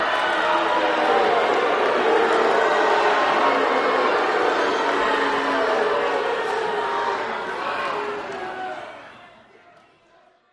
Crowd Boos During Fight, Left and Right